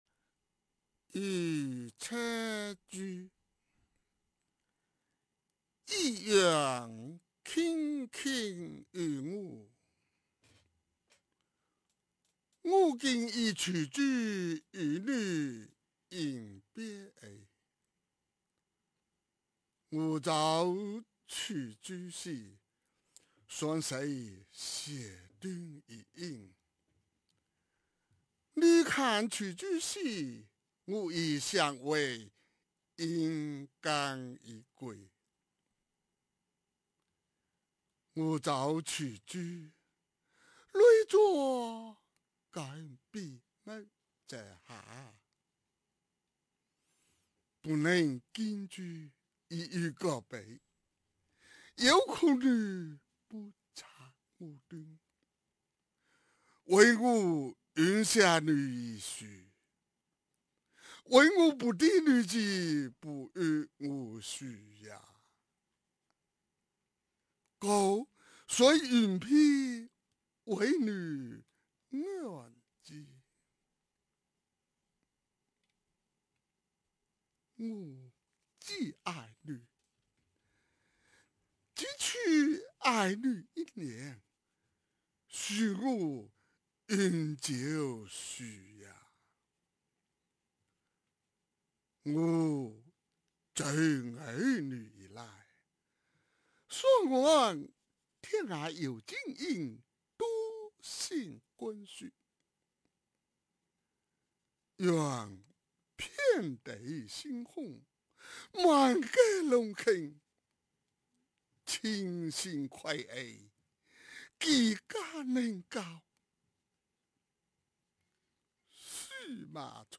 用福州话诠释《与妻书》